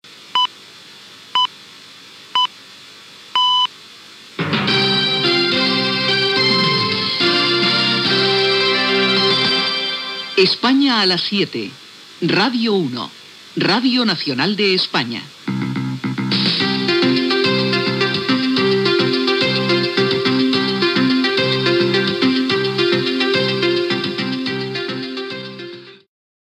Careta del programa